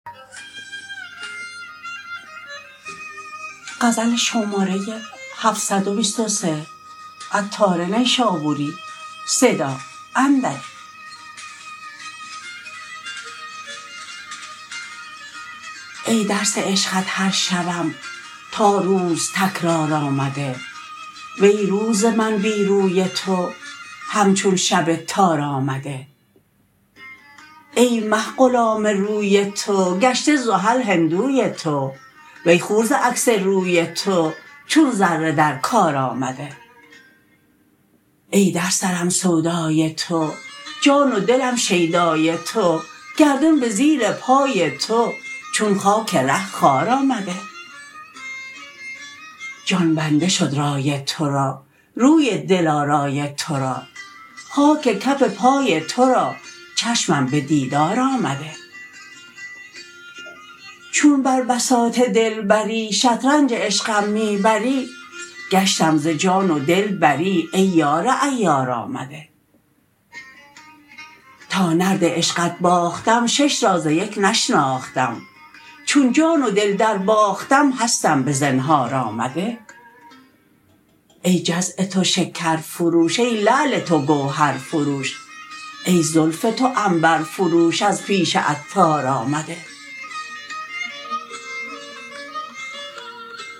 متن خوانش: